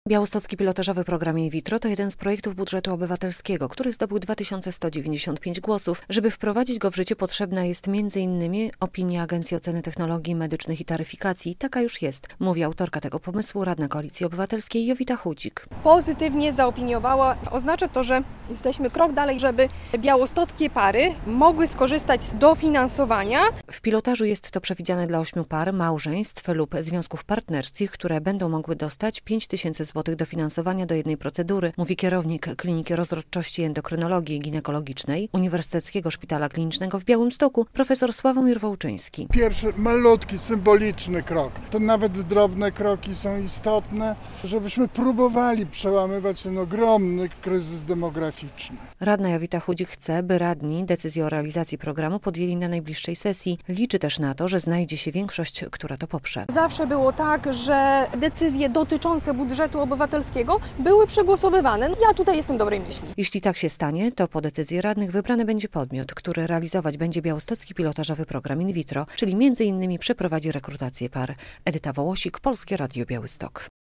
Ta opinia jest pozytywna, a to oznacza, że jesteśmy krok dalej, by białostockie pary mogły skorzystać z dofinansowania - mówi autorka tego pomysłu radna Koalicji Obywatelskiej Jowita Chudzik.